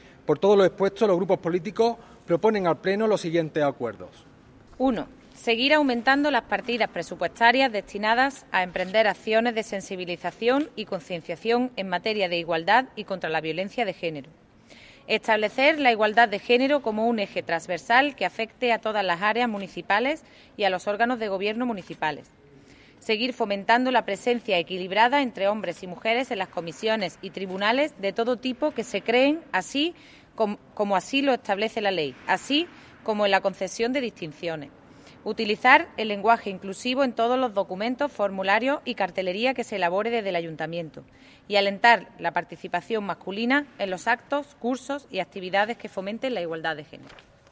El patio del Ayuntamiento de Antequera acogía en el mediodía de hoy martes 8 de marzo la lectura simbólica por parte de todos los grupos políticos municipales del manifiesto conjunto aprobado en el Pleno ordinario del pasado mes de febrero.
Entre los acuerdos más destacados que recogen dicho manifiesto, leídos por la teniente de alcalde Ana Cebrián, se incluyen:
Cortes de voz